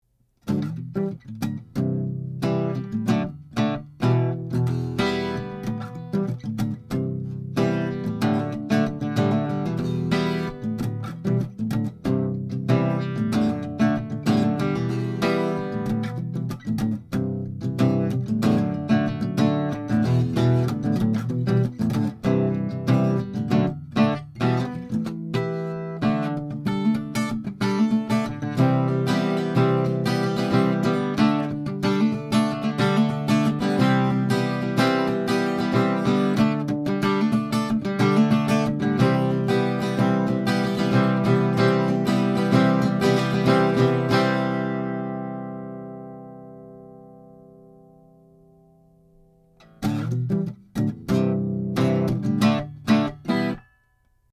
Blue Woodpecker Active Ribbon Mic, Figure 8 Pattern
Tracked through a Warm Audio WA12 into a Metric Halo ULN8 converter. No EQ, compression, effects.
1933 GIBSON L10 ARCHTOP
L10WoodpkrWA12Harmonic.mp3